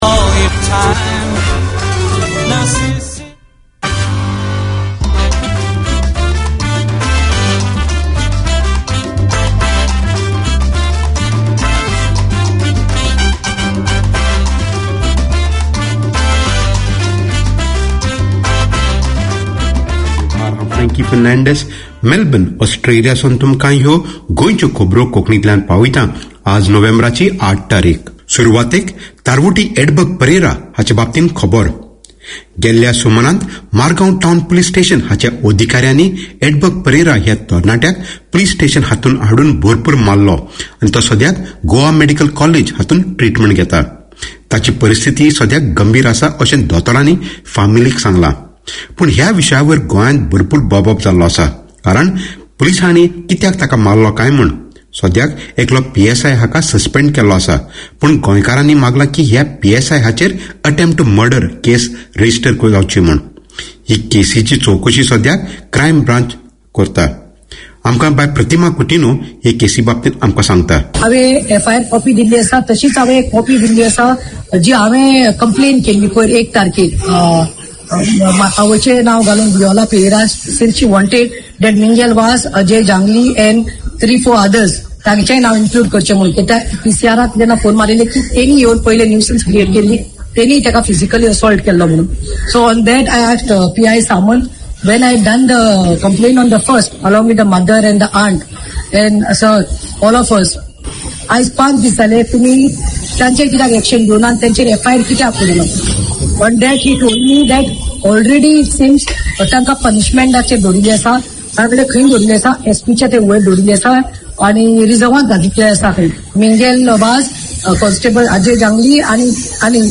Radio made by over 100 Aucklanders addressing the diverse cultures and interests in 35 languages.
Your hosts, the Good Guys, play a rich selection of old and contemporary Konkani music, talk with local community personalities, present short radio plays, connect with community events and promote the culture and traditions.